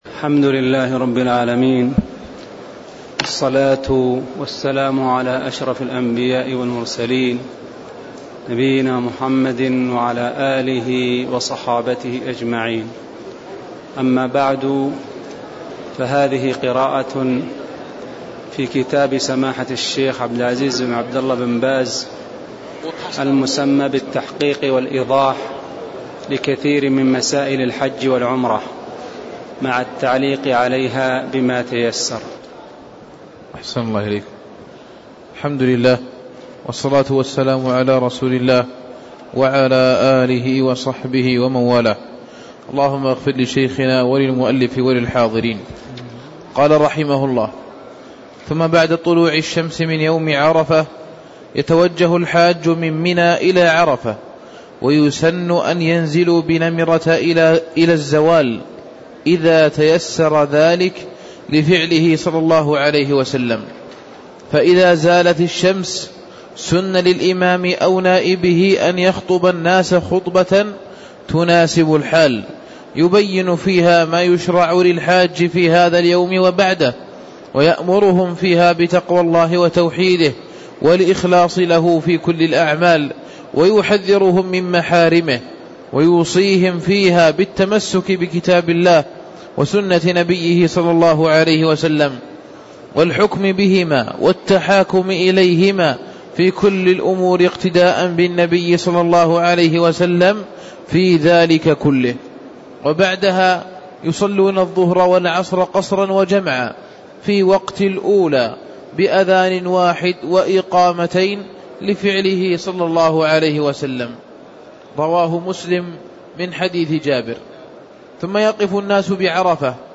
تاريخ النشر ١٧ ذو القعدة ١٤٣٦ هـ المكان: المسجد النبوي الشيخ: فضيلة الشيخ عبدالله بن محمد آل خنين فضيلة الشيخ عبدالله بن محمد آل خنين من قوله: ثمّ بعد طلوع الشمس من يوم عرفة (04)